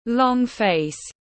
Long face /lɒŋ feɪs/